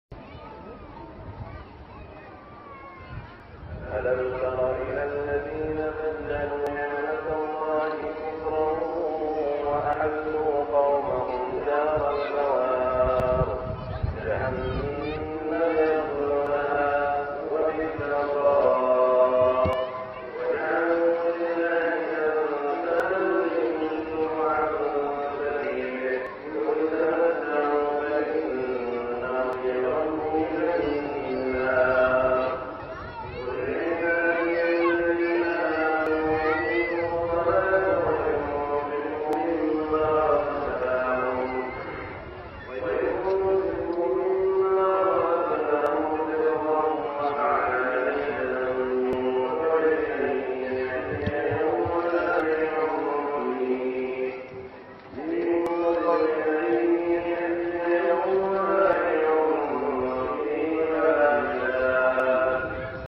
صلاة المغرب في الهند من سورة إبراهيم 28-31 > زيارة الشيخ سعود الشريم لدولة الهند > تلاوات و جهود الشيخ سعود الشريم > المزيد - تلاوات الحرمين